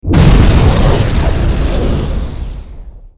magic-atom.wav.mp3